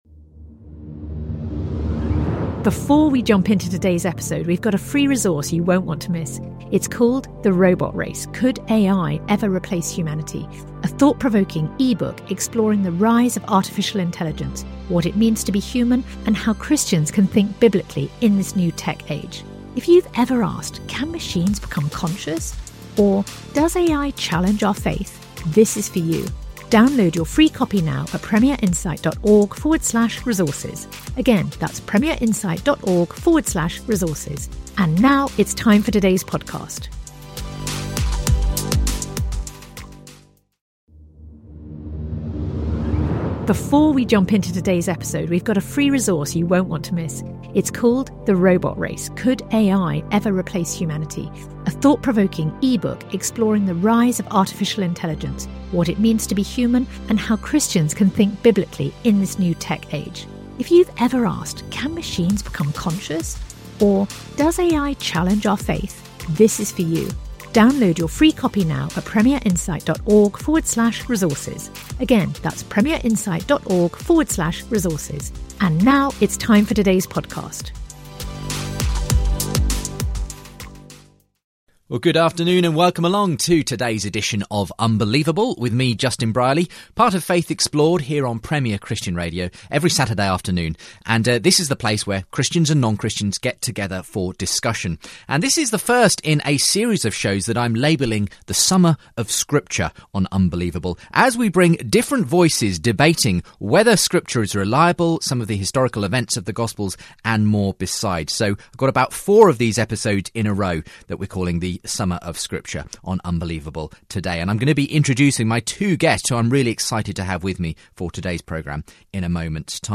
They debate whether inerrancy makes any difference, the authorship of the Gospels and apparent contradictions between them.